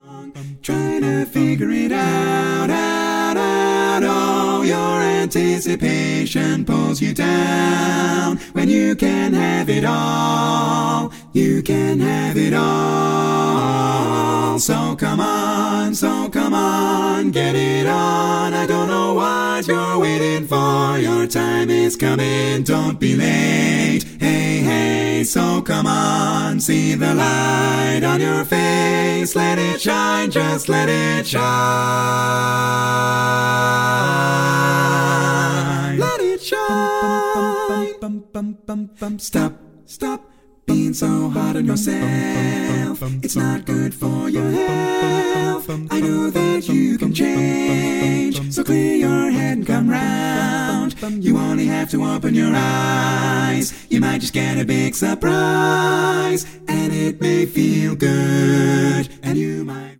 Category: Female